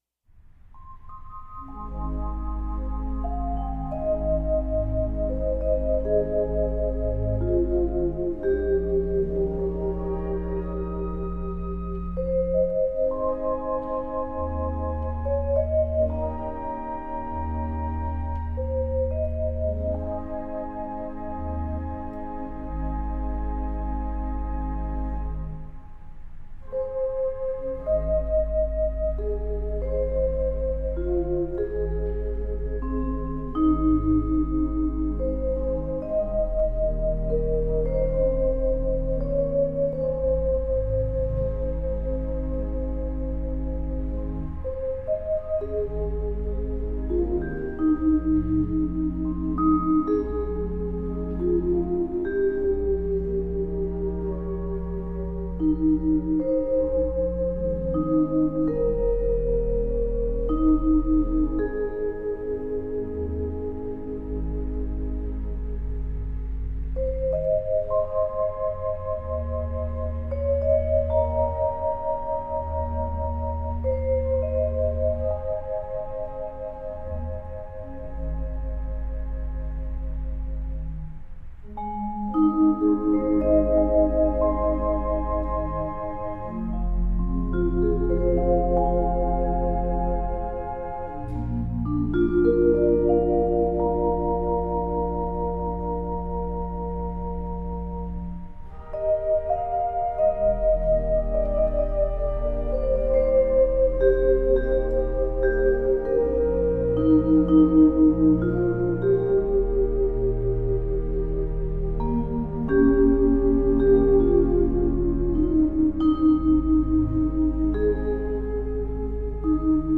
vibraharp_organ_2.mp3